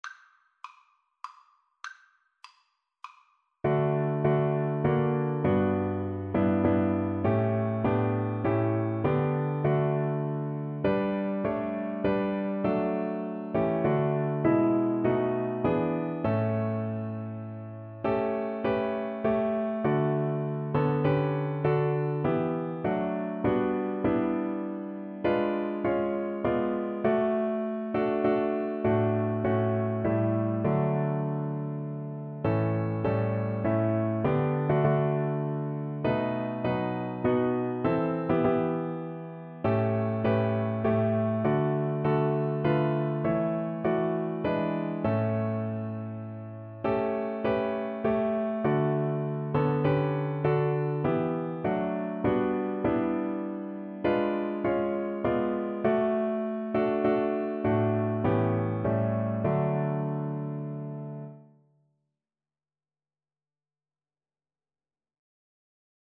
3/4 (View more 3/4 Music)
Classical (View more Classical Viola Music)